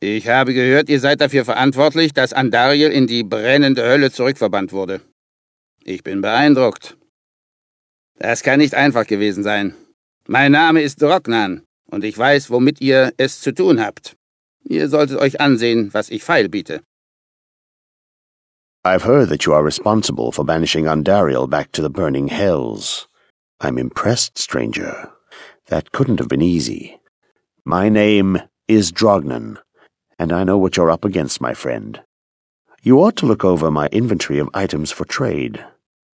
Als deutscher Spieler ist man ja schlechte Sprecher gewöhnt, aber so schlecht wie hier - es wechselt sogar der Sprecher des wichtigsten NPCs (Cain) zwischen Basisspiel und Erweiterung - trifft es einen nicht oft.
Hier noch ein paar Negativbeispiele: Kashya, Gheed,